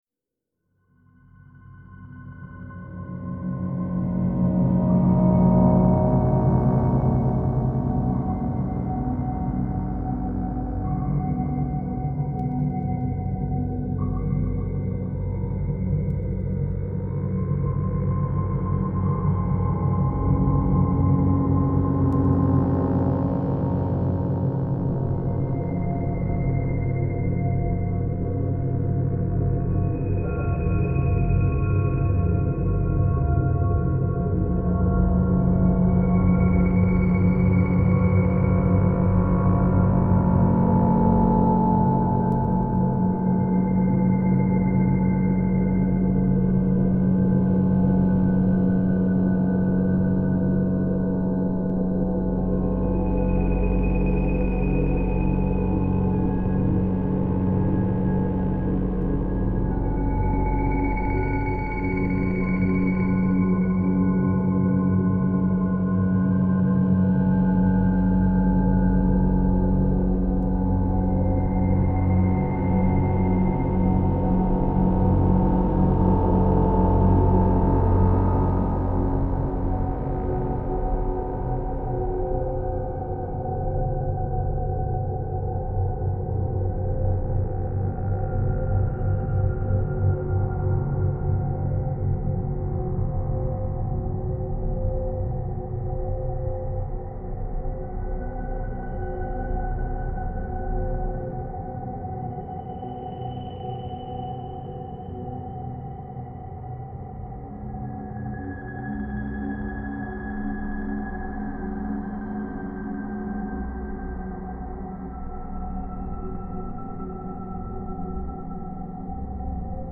Genre: Dark Ambient.